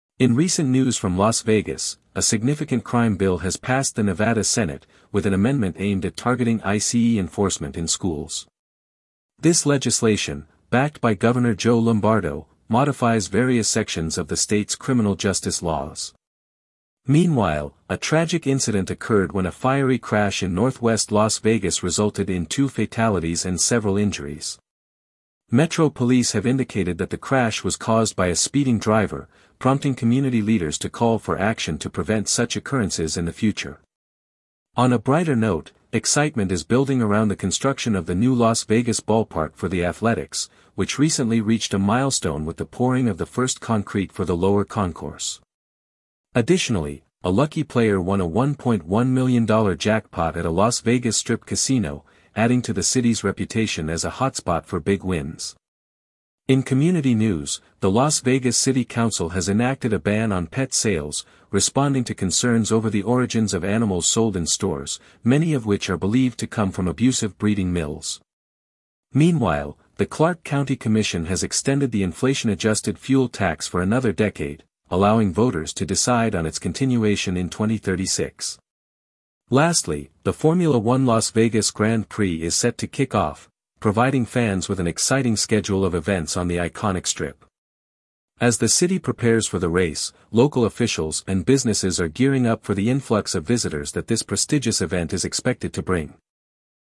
Regional News